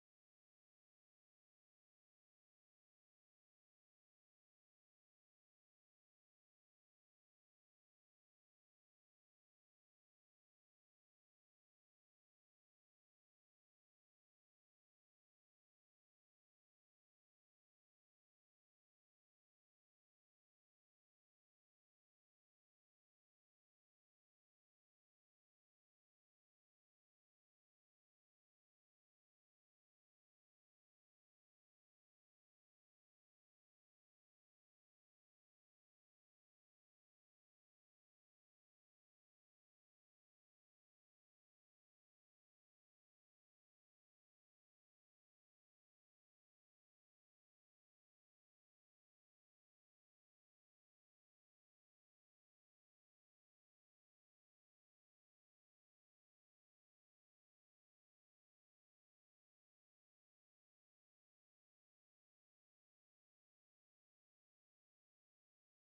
Lügenlieder:
Tonart: G-Dur
Taktart: 6/8
Besetzung: vokal